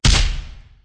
traphit_5.ogg